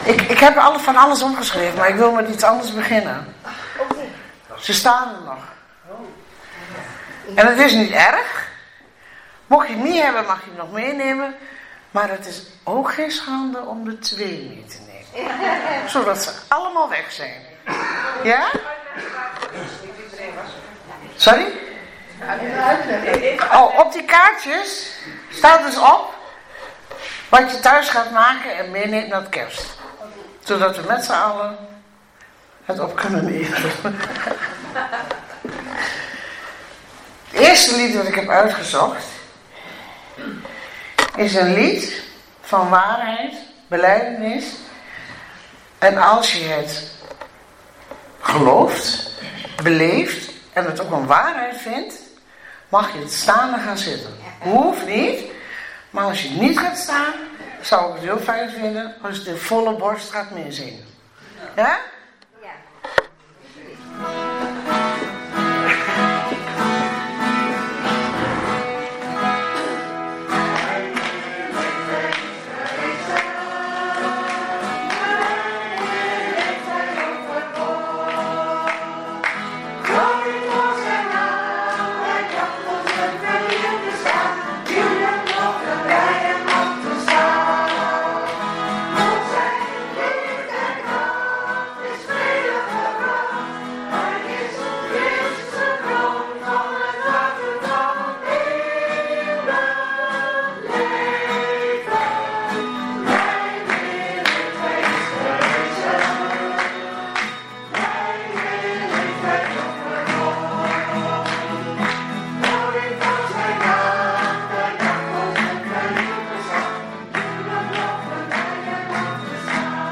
14 december 2025 dienst - Volle Evangelie Gemeente Enschede